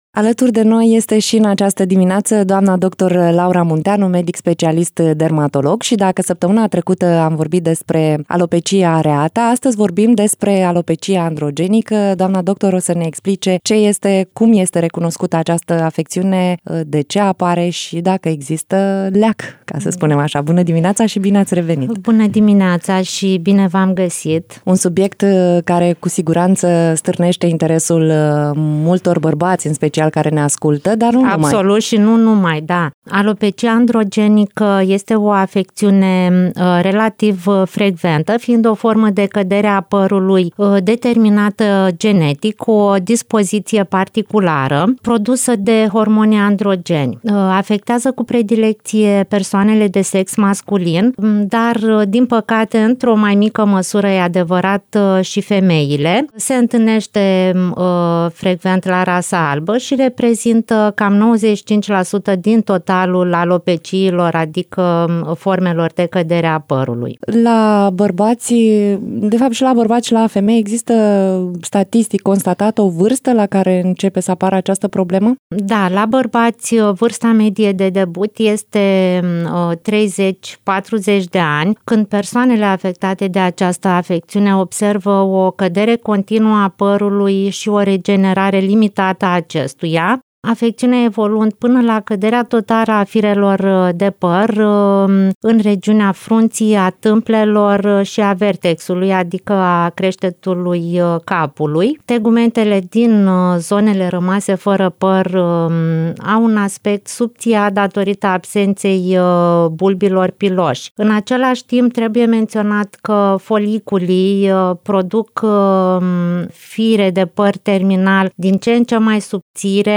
în direct la Radio Iaşi.
interviu